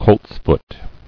[colts·foot]